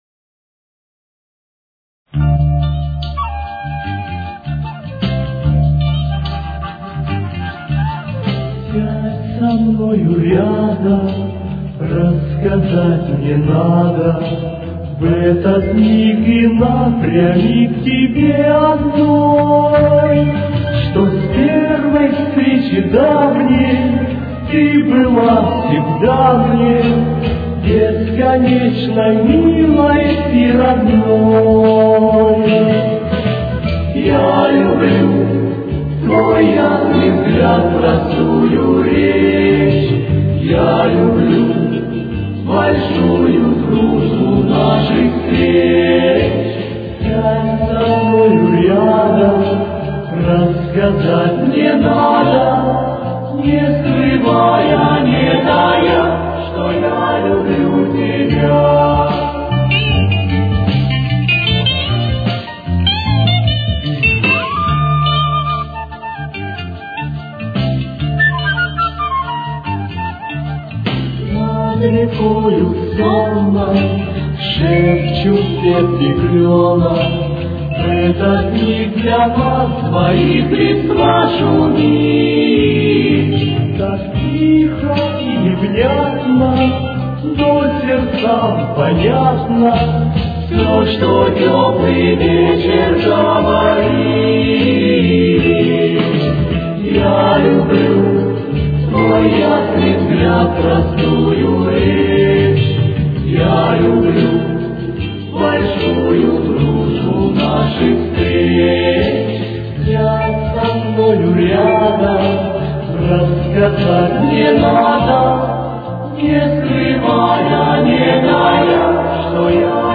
с очень низким качеством (16 – 32 кБит/с)
Тональность: Ре минор. Темп: 75.